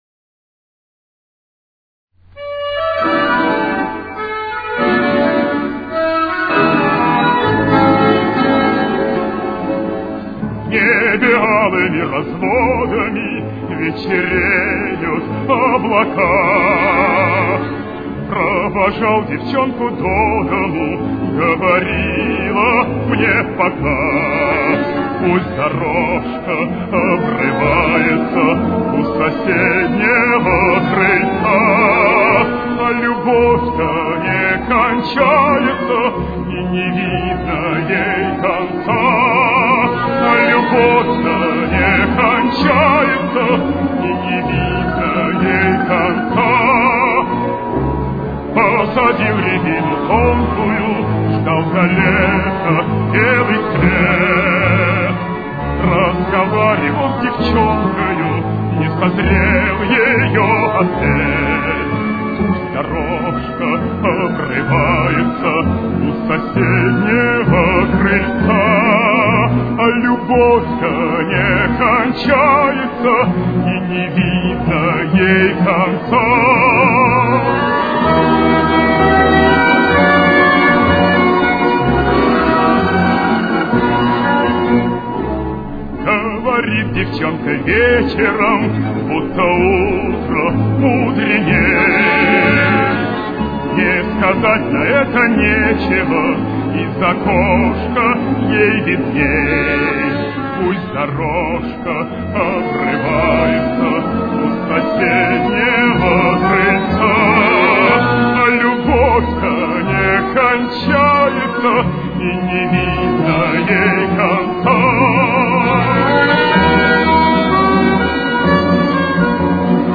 с очень низким качеством (16 – 32 кБит/с).
Темп: 129.